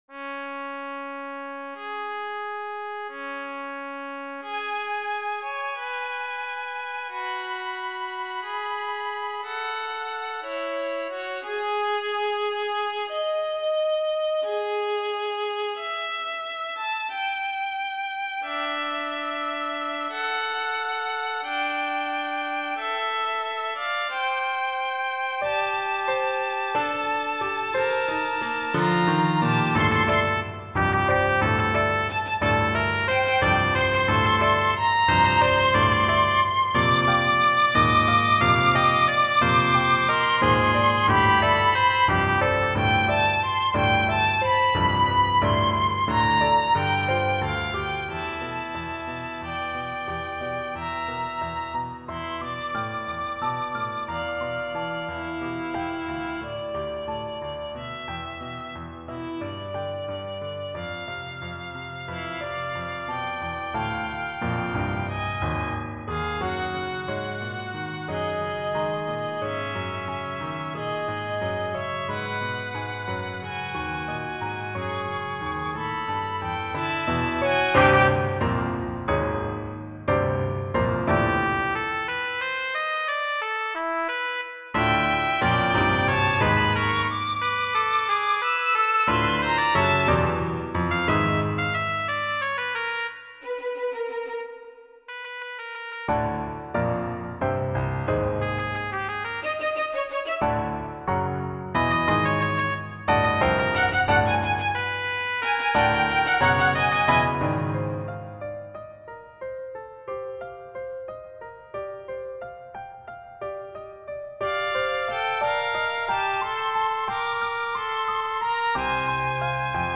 Music for trumpet, violin, and piano.